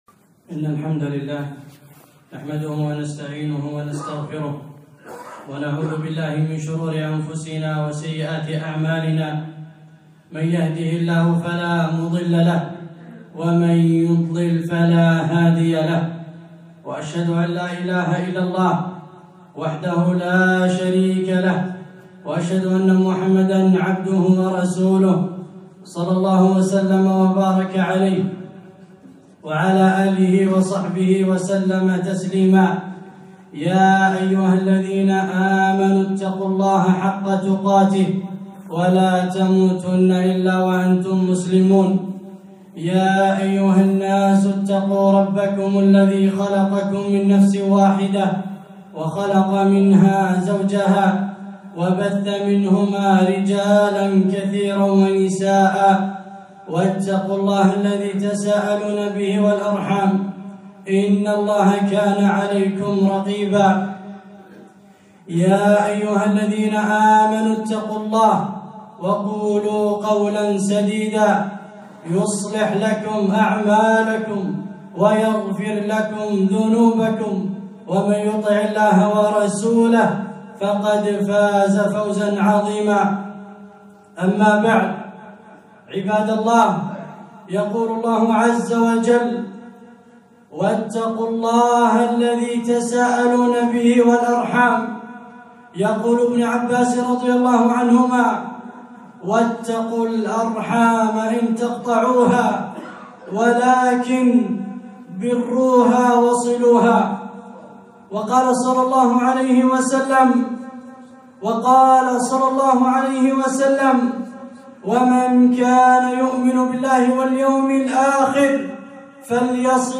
خطبة - صلة الرحم